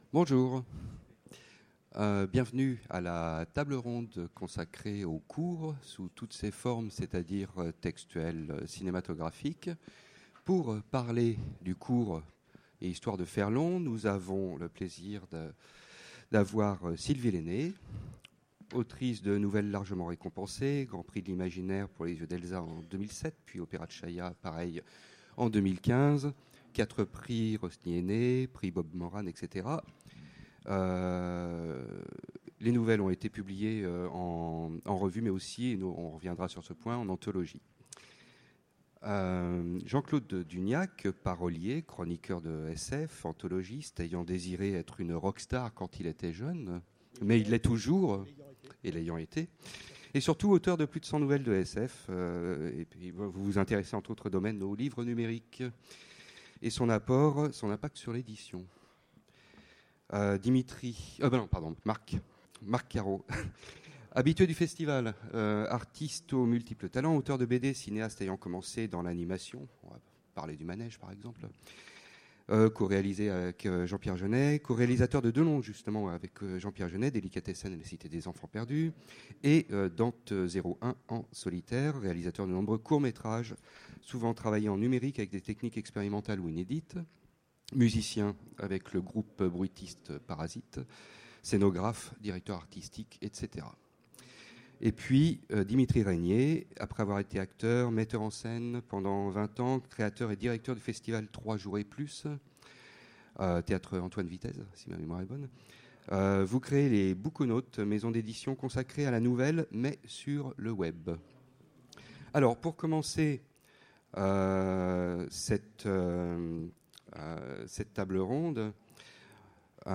Utopiales 2017 : Conférence Courts toujours